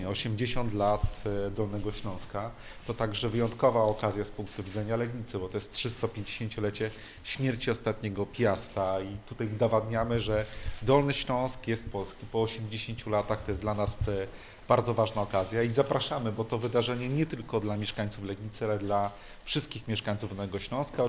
Gospodarz wydarzenia, Maciej Kupaj – prezydent Legnicy, podkreśla, że miasto ma szczególne znaczenie w historii regionu.